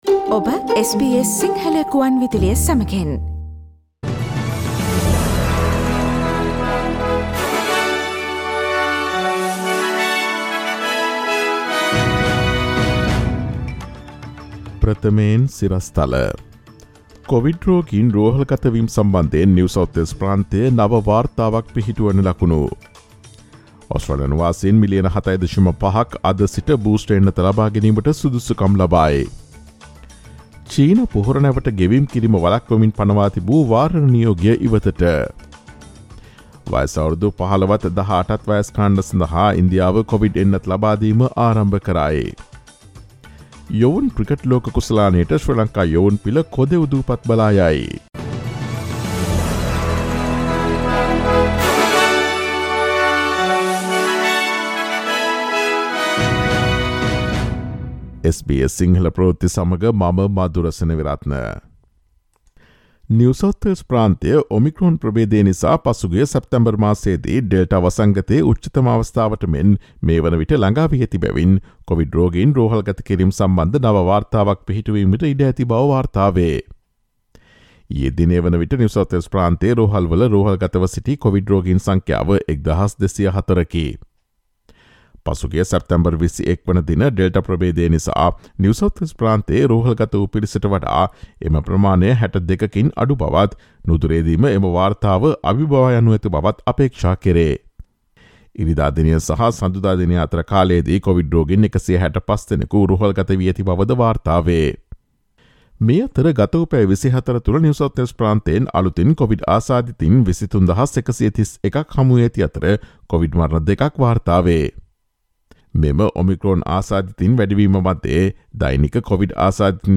ඔස්ට්‍රේලියාවේ සහ ශ්‍රී ලංකාවේ නවතම පුවත් මෙන්ම විදෙස් පුවත් සහ ක්‍රීඩා පුවත් රැගත් SBS සිංහල සේවයේ 2022 ජනවාරි 04 වන දා අඟහරුවාදා වැඩසටහනේ ප්‍රවෘත්ති ප්‍රකාශයට සවන් දීමට ඉහත ඡායාරූපය මත ඇති speaker සලකුණ මත click කරන්න.